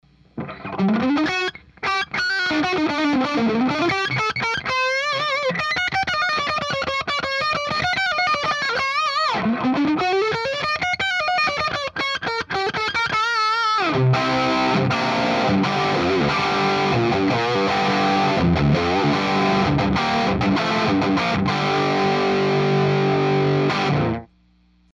It sounds alot better. Less splattery and messy.
Quick noodling clip with the current configuration. Straight to the amp. There's more gain on tap, but its not quite controllable yet. Oh yeah, no boosts, just guitar-> amp -> cab miked up with 1 SM57 Attachments Hose C.mp3 Hose C.mp3 976.7 KB · Views: 209